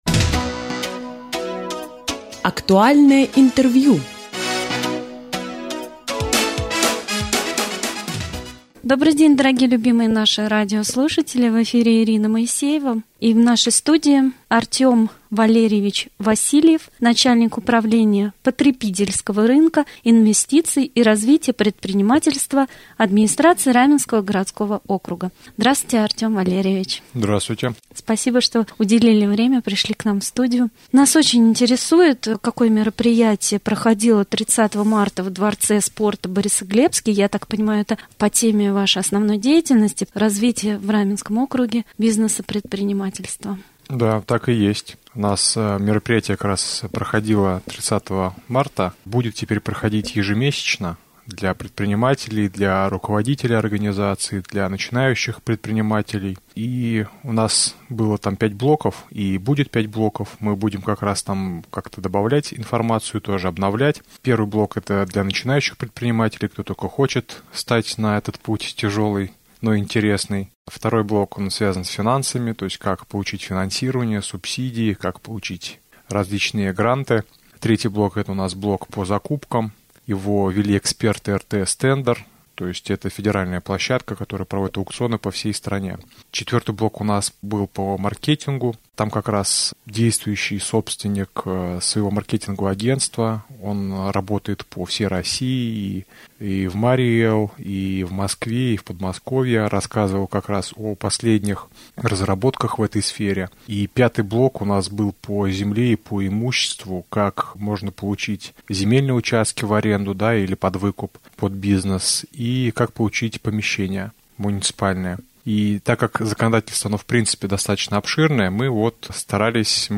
Артем Васильев, начальник Управления потребительского рынка, инвестиций и развития предпринимательства администрации Раменского г.о. стал гостем передачи «Актуальный разговор».